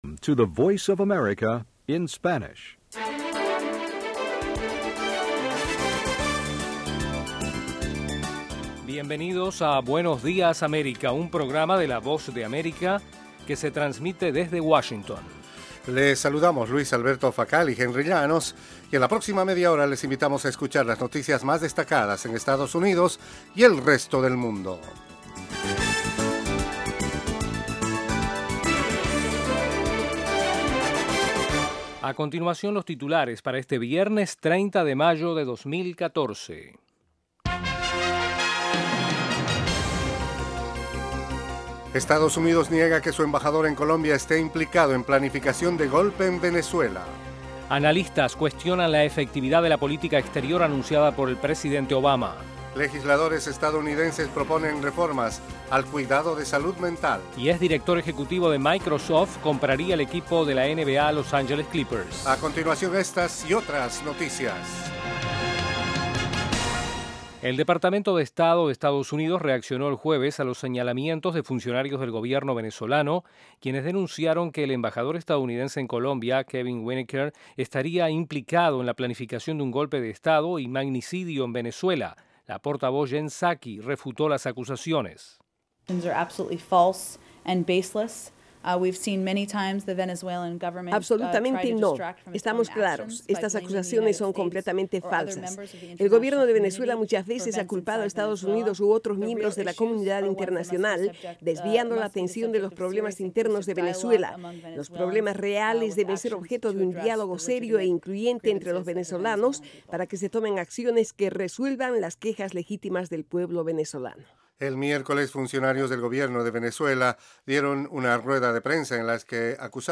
Buenos días América es un programa informativo diario de media hora dirigido a nuestra audiencia en América Latina. El programa se transmite de lunes a viernes de 8:30 a.m. a 9:00 a.m. [hora de Washington].